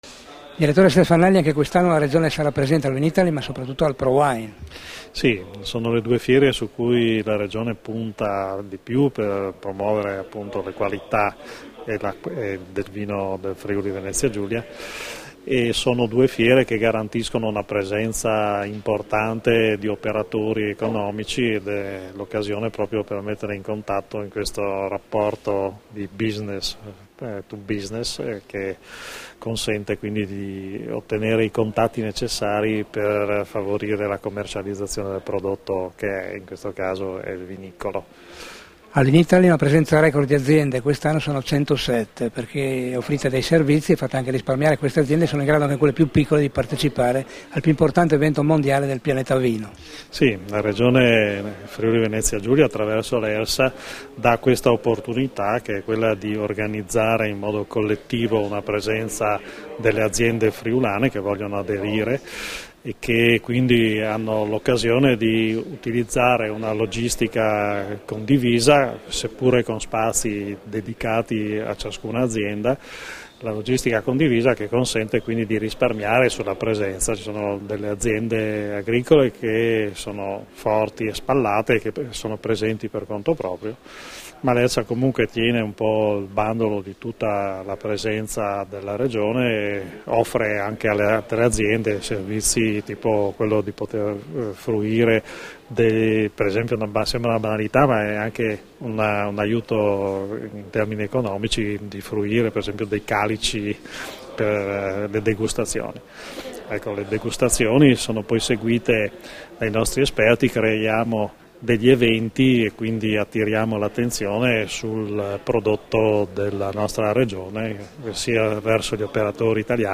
alla presentazione della partecipazione del Friuli Venezia Giulia alla quarantottesima edizione di "vinitaly" a Verona e alla ventesima edizione di "ProWein" a Düsseldorf (D), rilasciate a Udine il 19 marzo 2014